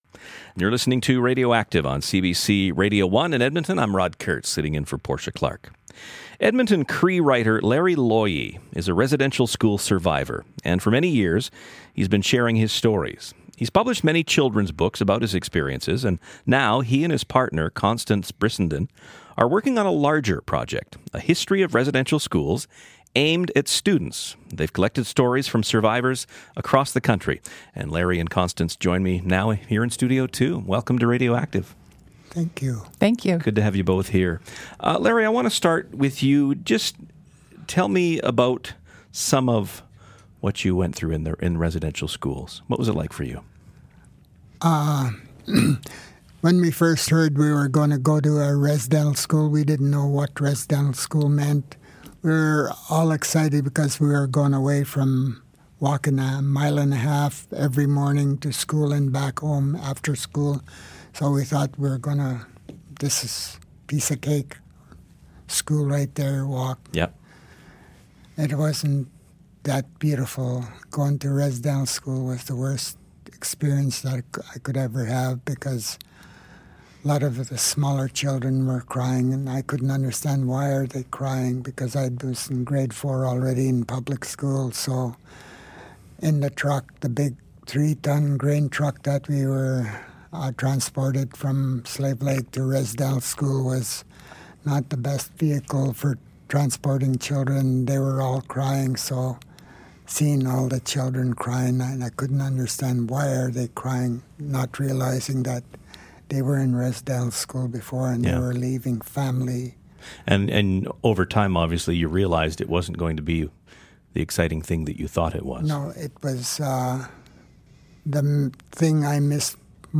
An Interview with Shelagh Rogers